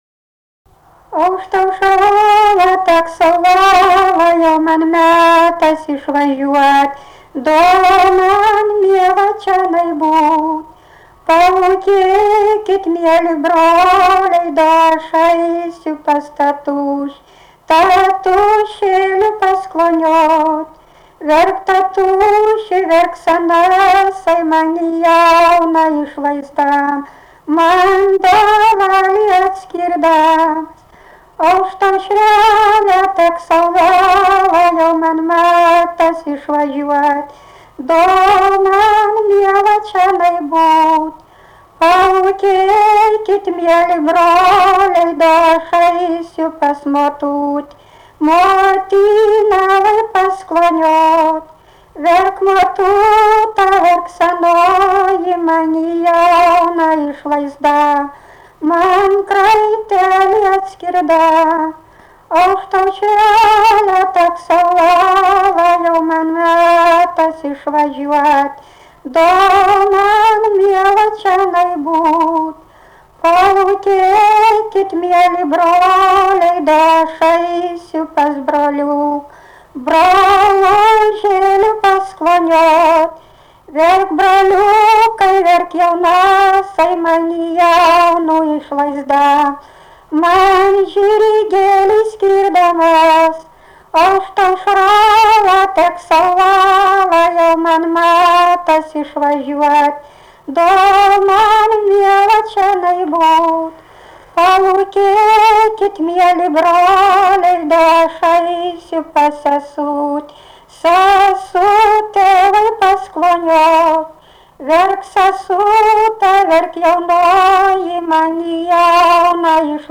daina, žaidimai ir rateliai
Erdvinė aprėptis Suvainiai
Atlikimo pubūdis vokalinis
Komentaras pabaigoj kaip žaisti